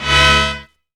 BROTHER STAB.wav